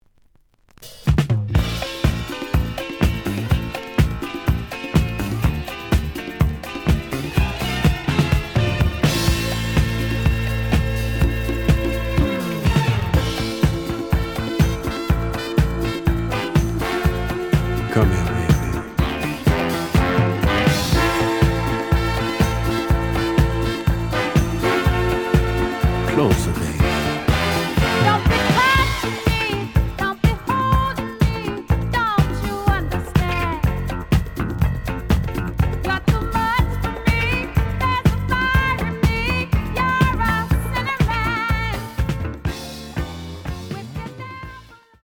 The audio sample is recorded from the actual item.
●Genre: Disco
Some click noise on B side due to scratches.